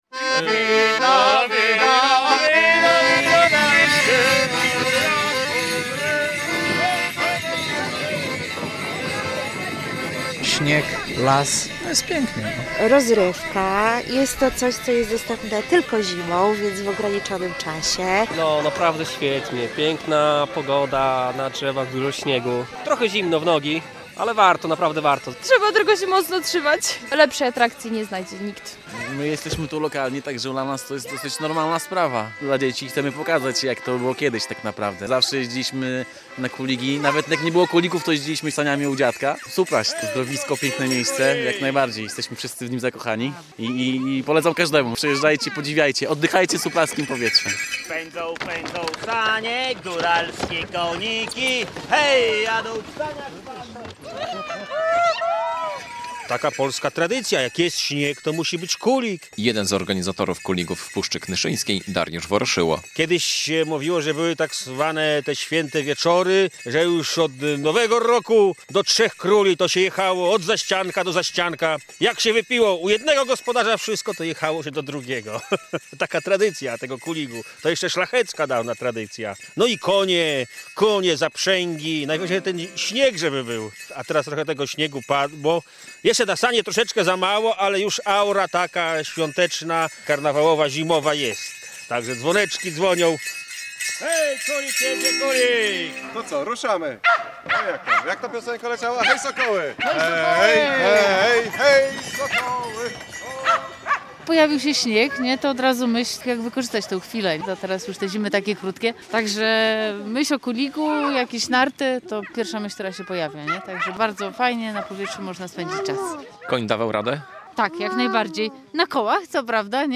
Wiadomości - Pogoda sprzyja organizacji kuligów